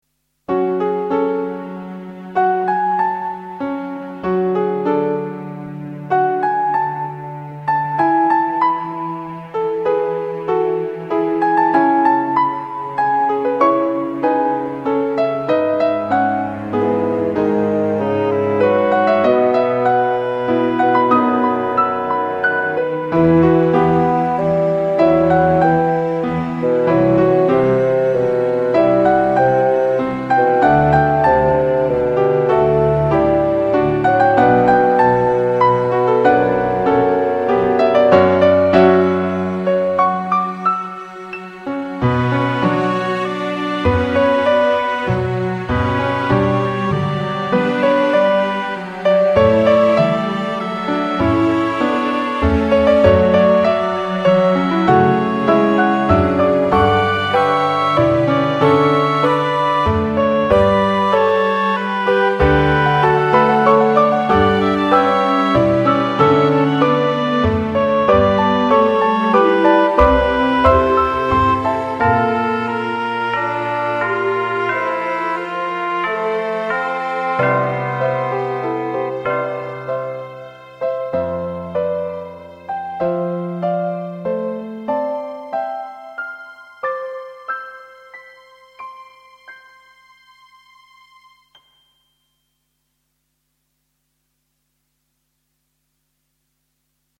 klassiek 1:37